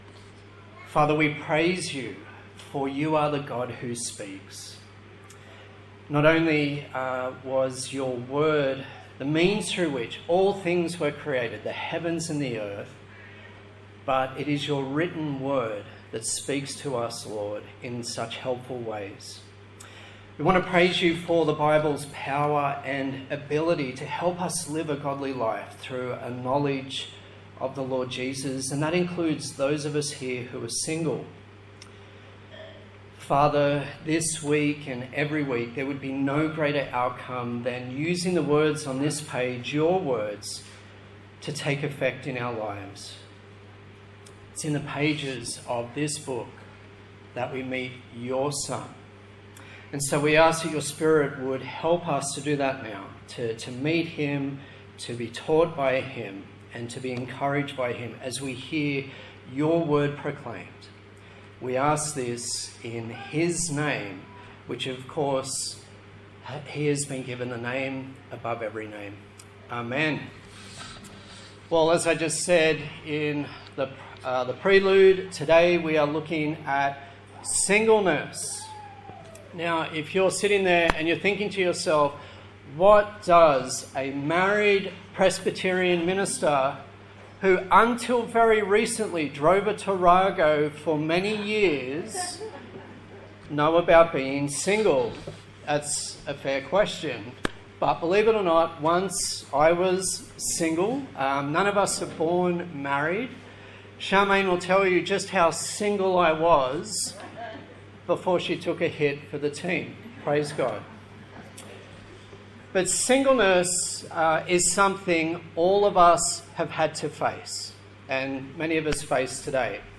1 Corinthians Passage: 1 Corinthians 7:7-9, 25-40 Service Type: Sunday Morning